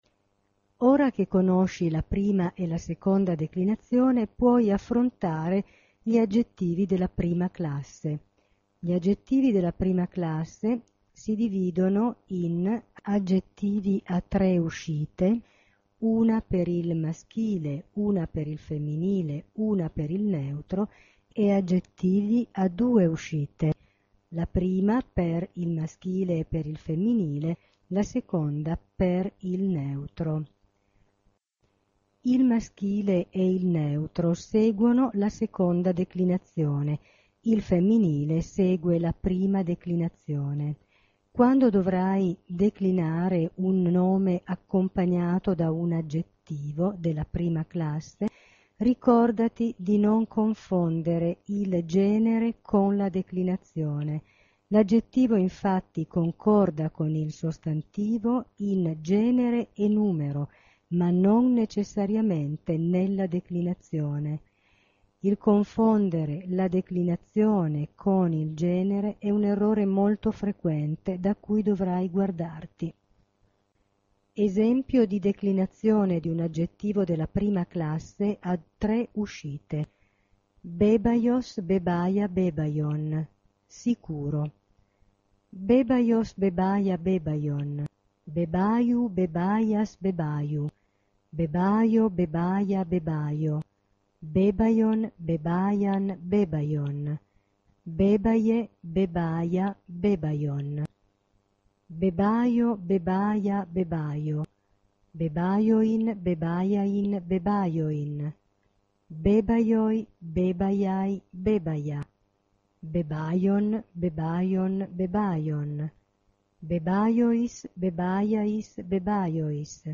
aggettivi della prima classe) permette di sentire la lettura degli aggettivi della prima classe con declinazione regolare.